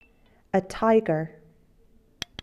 animal4 tiger
animal4-tiger.mp3